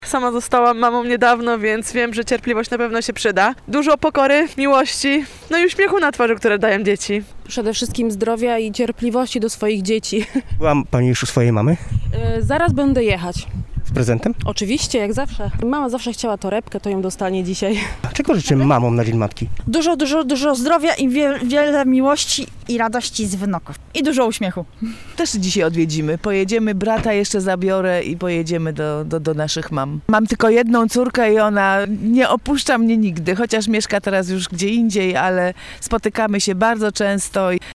Spytaliśmy zielonogórzan jak obchodzą ten dzień i czego życzą swoim mamom: